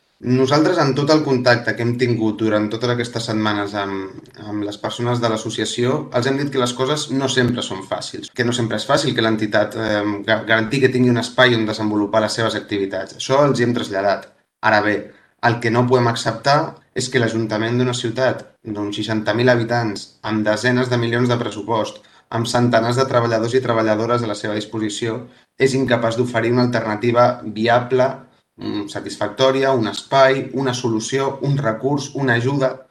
El portaveu de Guanyem,